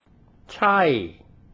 ใช่  chaiF
pronunciation guide
Royal Thai General Systemchai